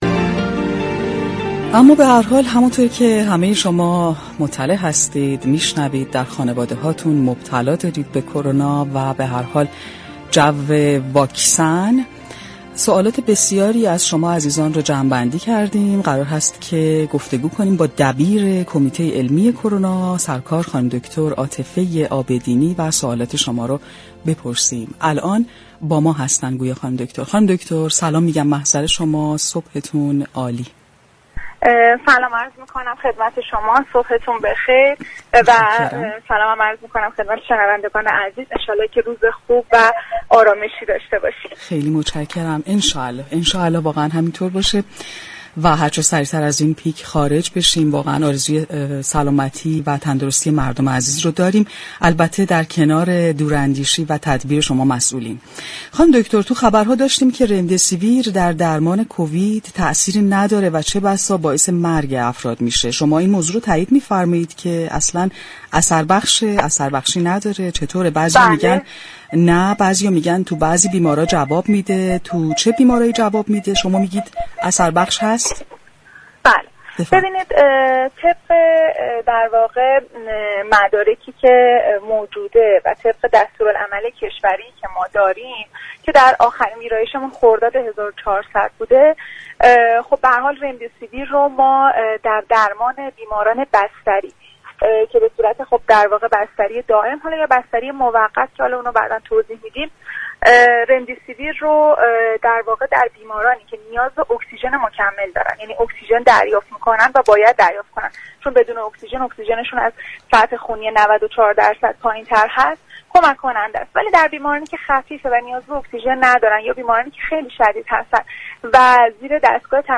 در گفتگو با برنامه تهران ما سلامت رادیو تهران